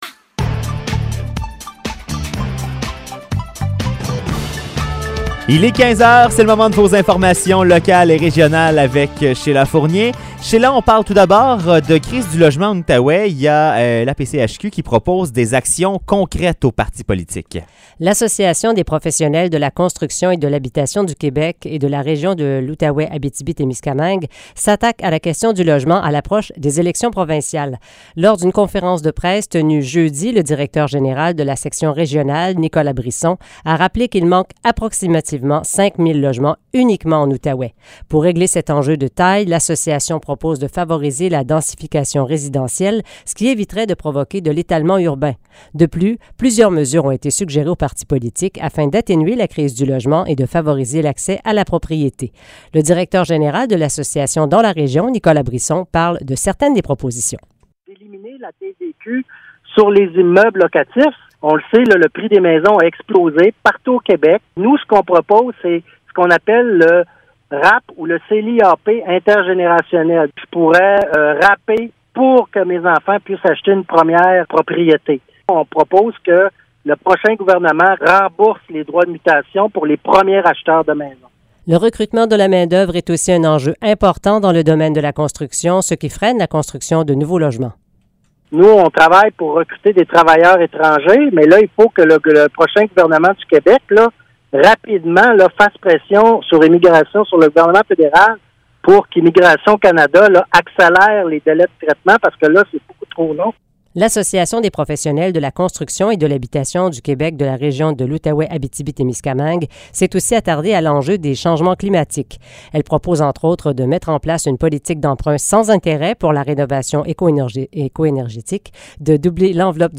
Nouvelles locales - 2 septembre 2022 - 15 h